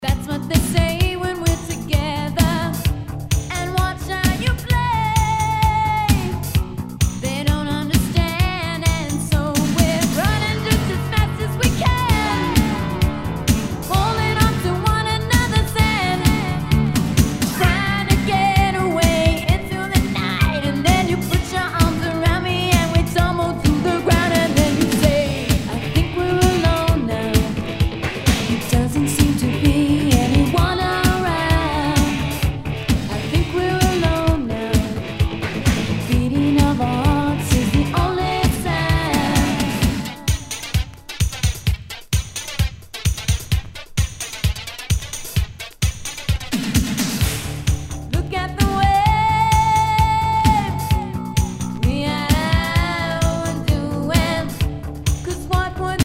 ROCK/POPS/INDIE
ナイス！シンセ・ポップ！
全体にチリノイズが入ります